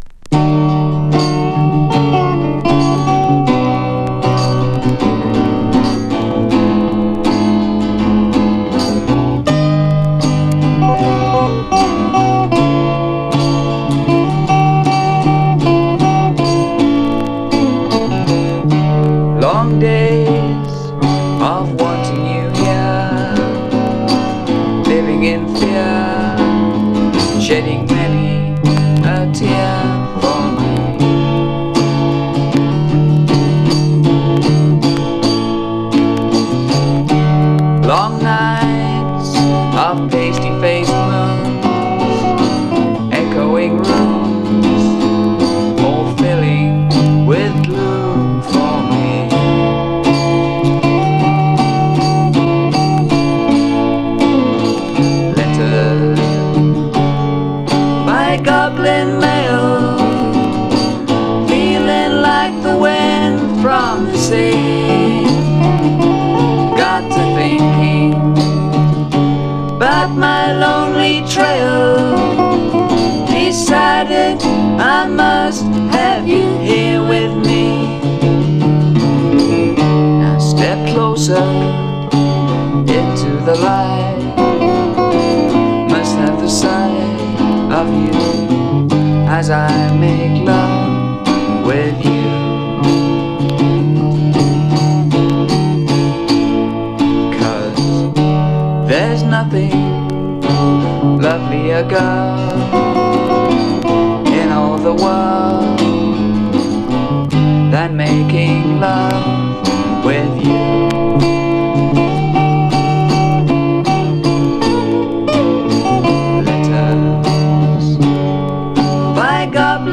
3. > PSYCHEDELIC/PROGRESSIVE/JAZZ ROCK